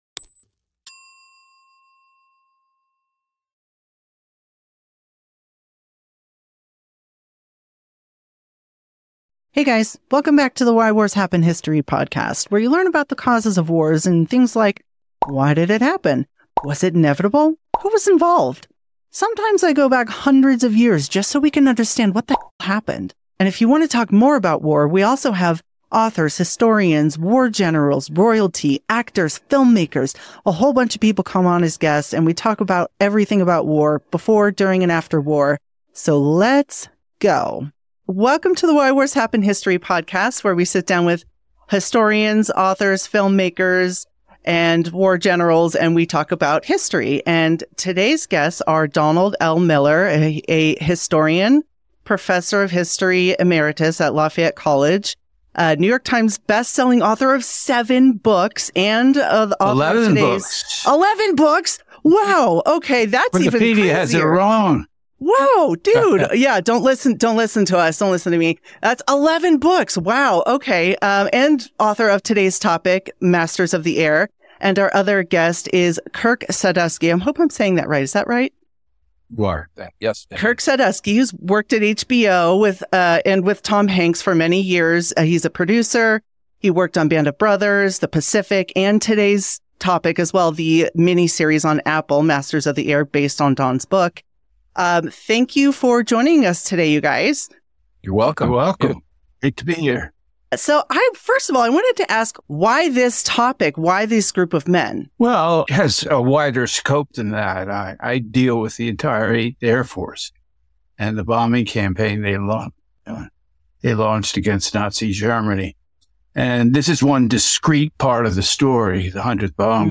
Why Wars Happened Interview History Podcast